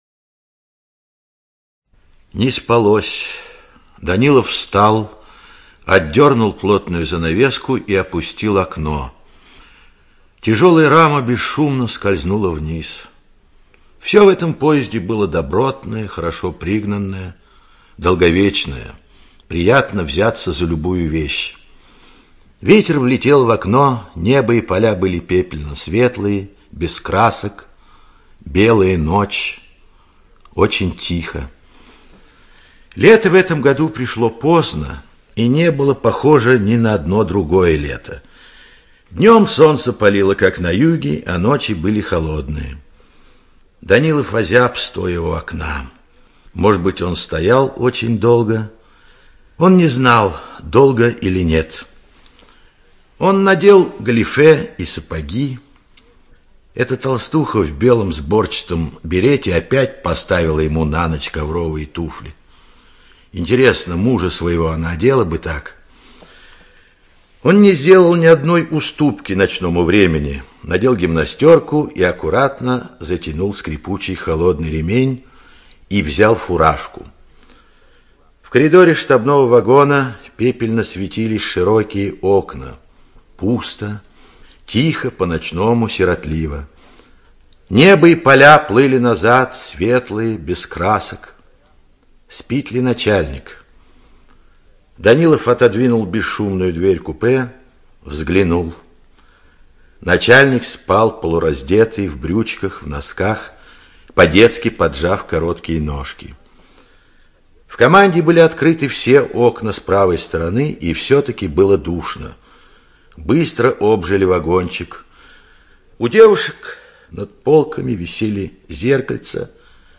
ЧитаетПапанов А.